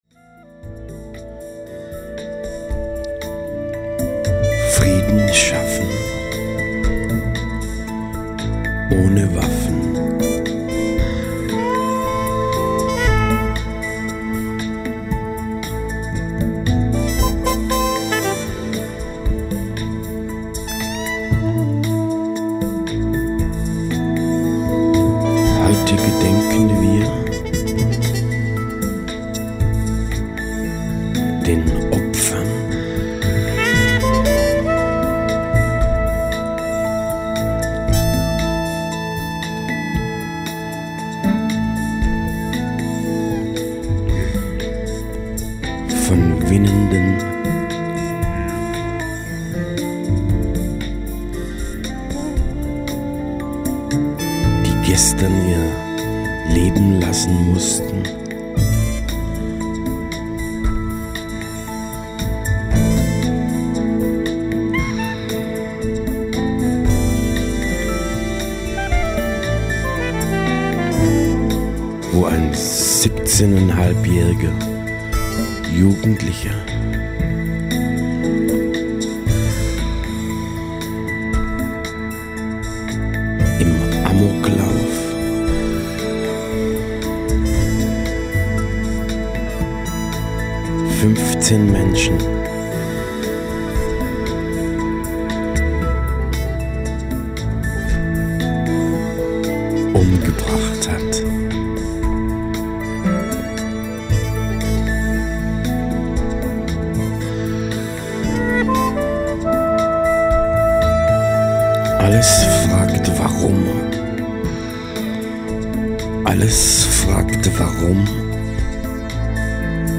(lange textversion zu winnenden 09